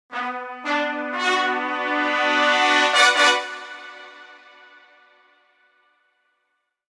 Winning Musical Phrase Sound Effect
A bright and recognizable musical phrase with brass trumpets announces important moments. It creates a sense of victory and success.
Genres: Sound Logo
Winning-musical-phrase-sound-effect.mp3